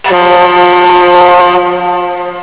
Horn
horn.wav